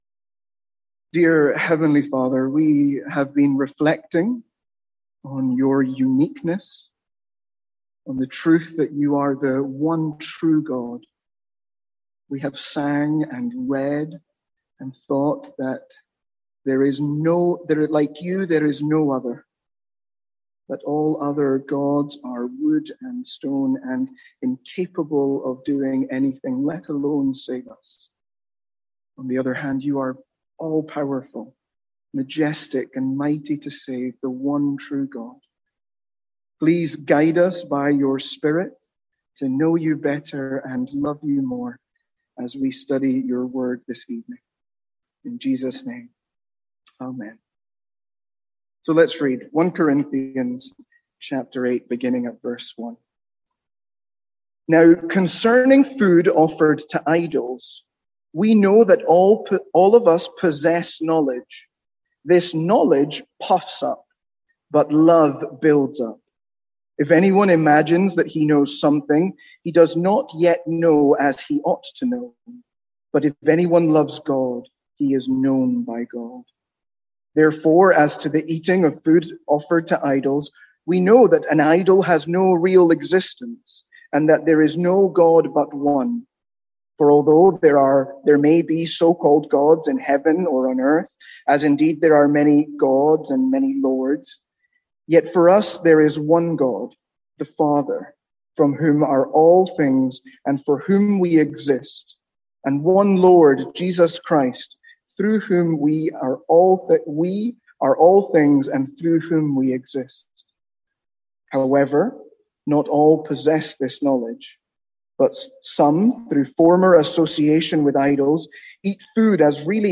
Sermons | St Andrews Free Church
From our evening series in 1 Corinthians.